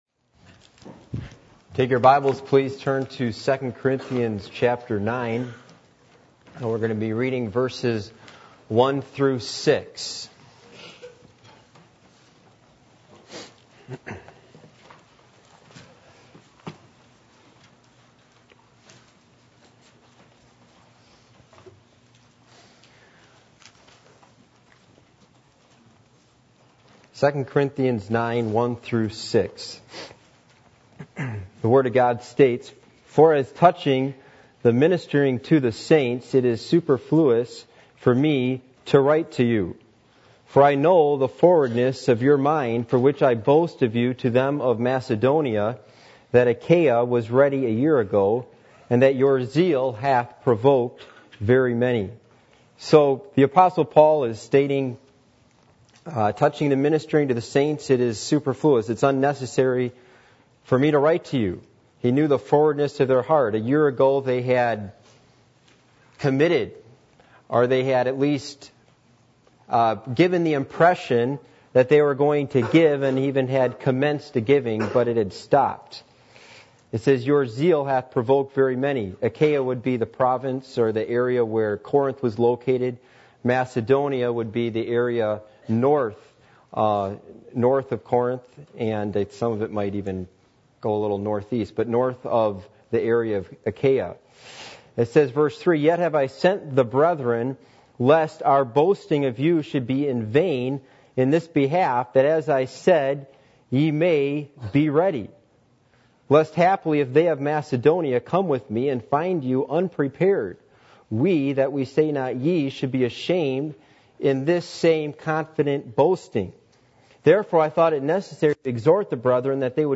Passage: 2 Corinthians 9:1-6 Service Type: Sunday Evening